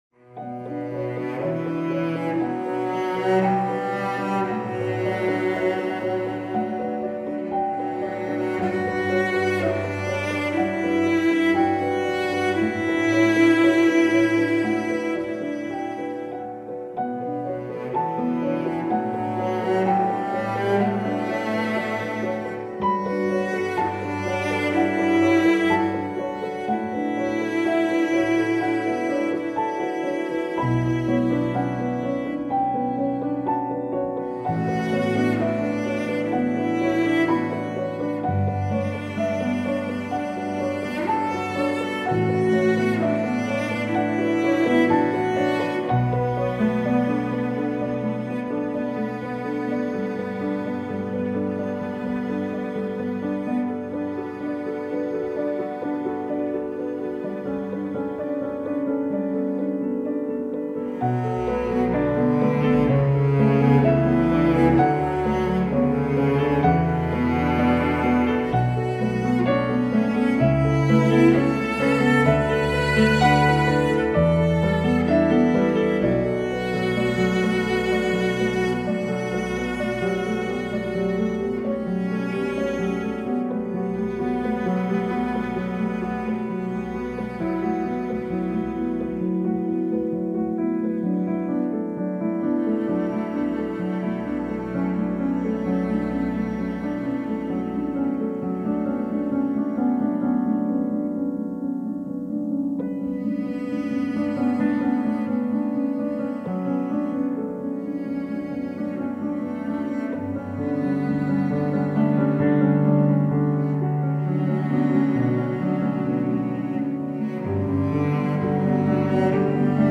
موسیقی بی کلام پیانو موسیقی بی کلام غم انگیز